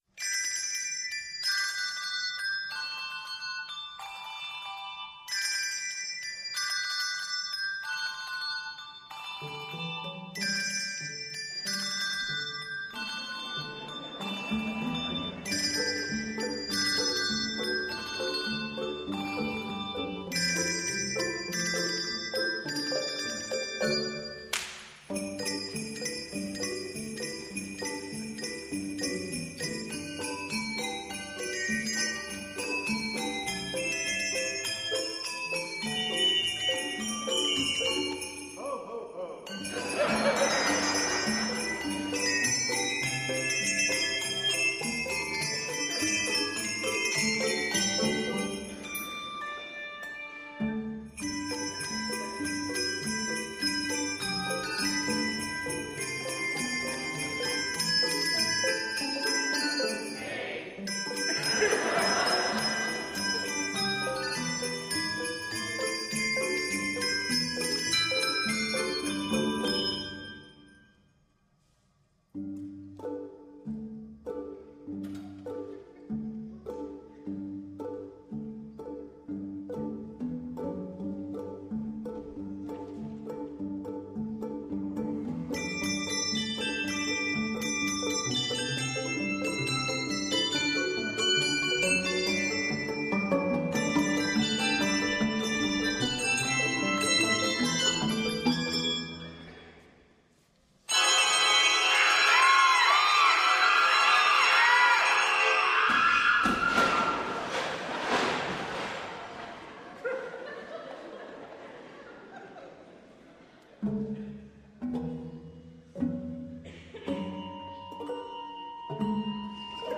Primarily set in G Major, it is 167 measures in length.